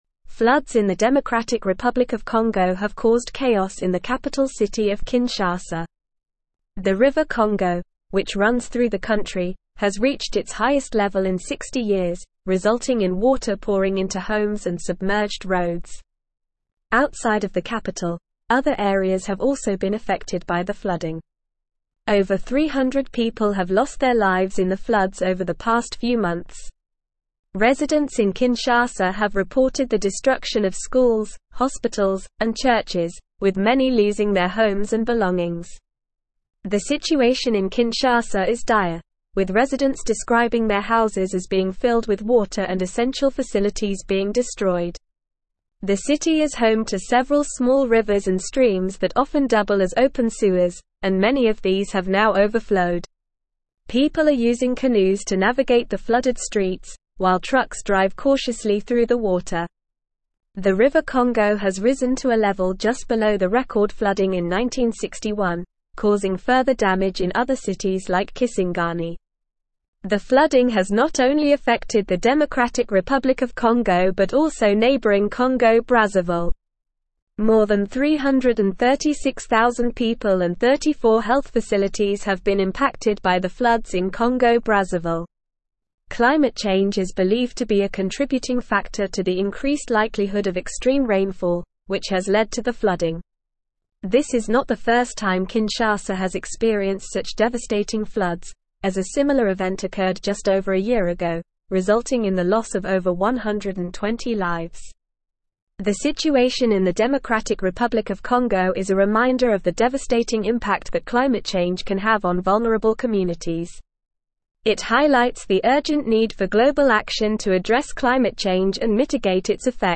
Normal
English-Newsroom-Advanced-NORMAL-Reading-Congos-Capital-Kinshasa-Devastated-by-Record-Floods.mp3